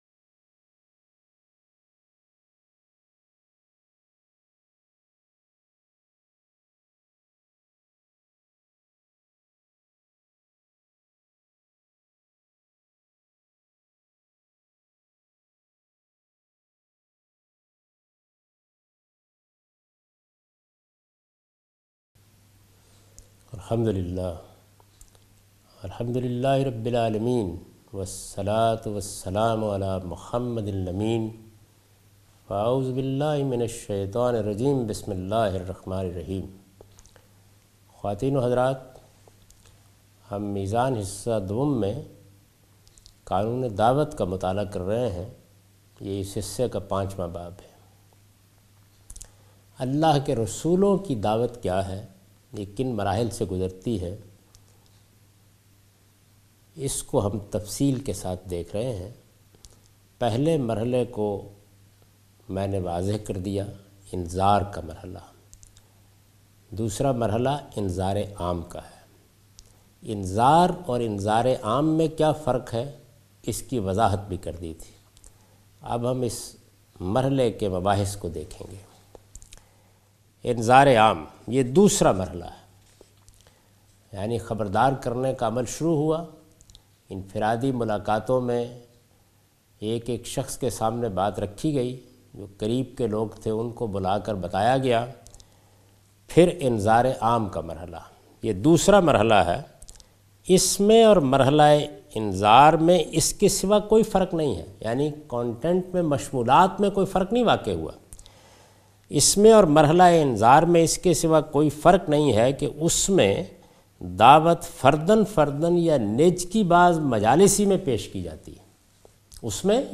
A comprehensive course taught by Javed Ahmed Ghamidi on his book Meezan. In this lecture series he will teach The Shari'ah of Preaching. First part of lecture contains the second step of Prophet's preaching Indhar i Am (open warning), the later part explains the third step 'Itmam e Hujjah (complete)' and a portion of fourth step 'Hijrah and Barah (Migration and Acquittal)' which is actually a part of prophetic tradition (Itmam e Hujjah).